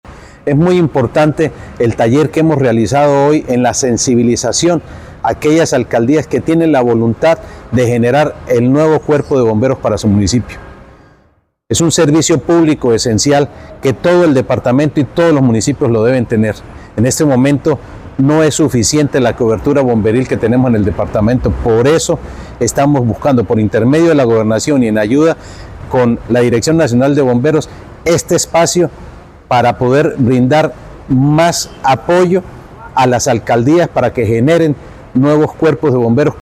1. Audio de Jhonny Peñaranda, secretario de Gobierno
Audio-de-Jhonny-Penaranda-secretario-de-Gobierno.mp3